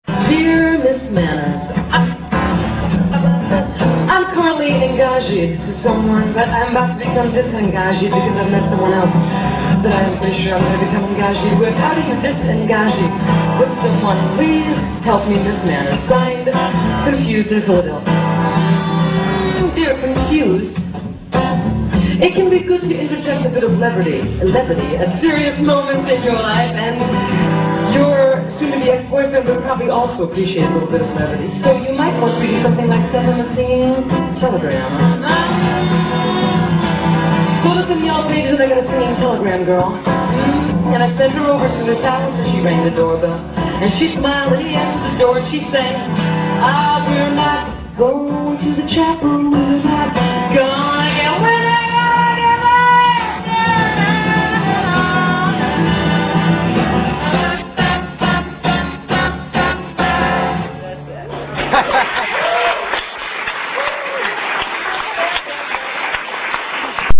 Concert Pictures and Movies
The Handlebar